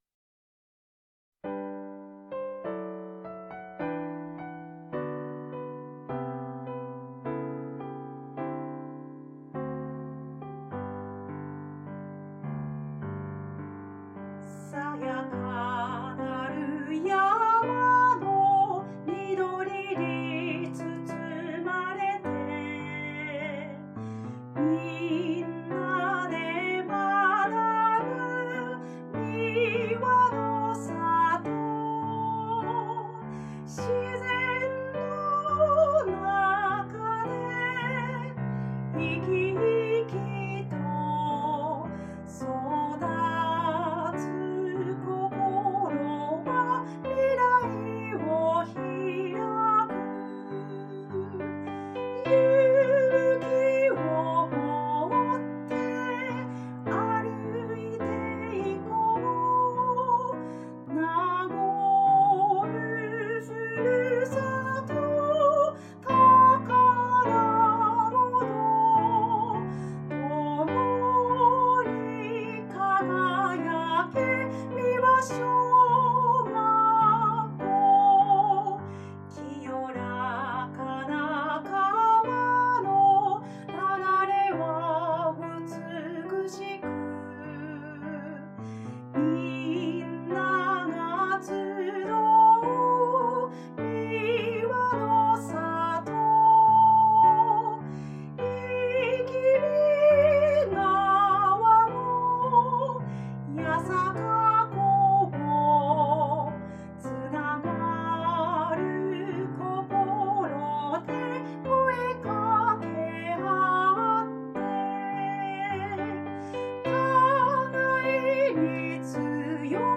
美和小学校校歌の音源（歌・ピアノ） [その他のファイル／2.43MB]